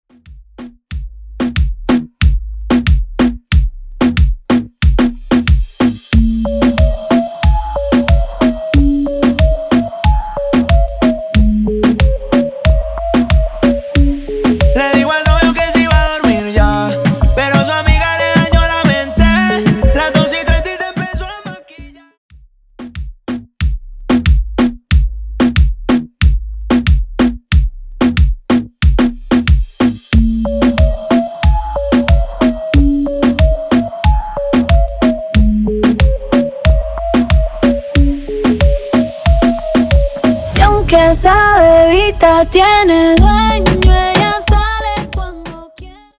Extended Dirty Intro, Coro